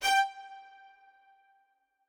strings1_11.ogg